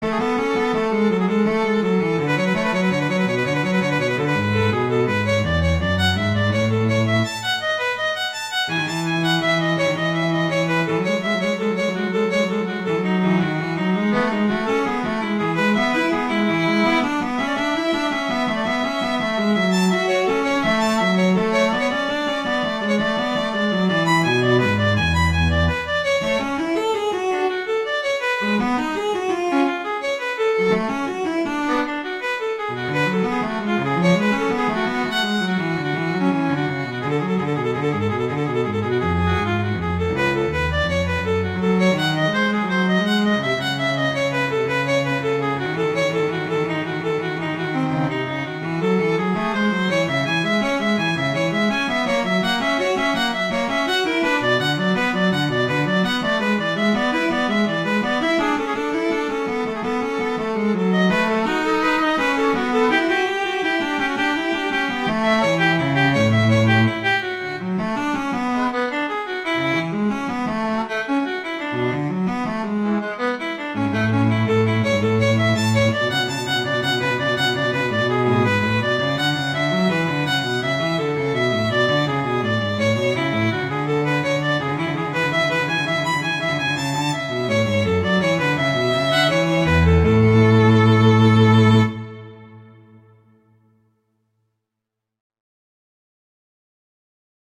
transcription for violin and cello
classical